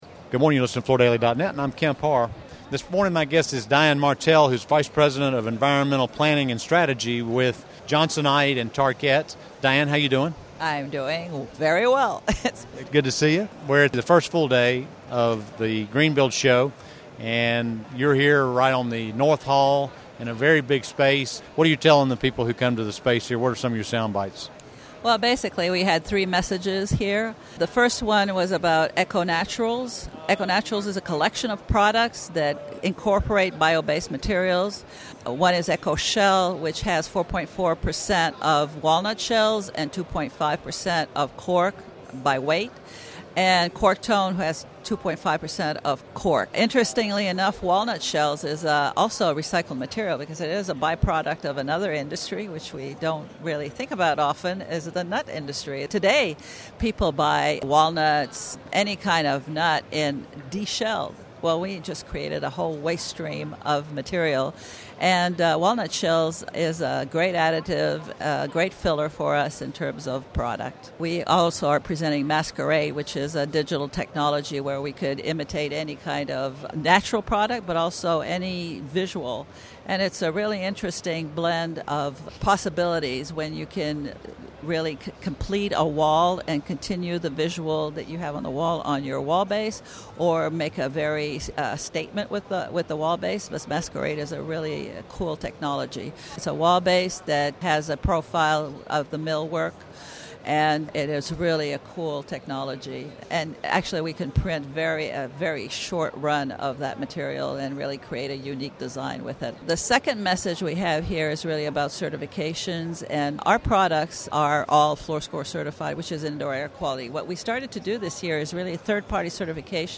Related Topics:Greenbuild International Conference and Expo, Tarkett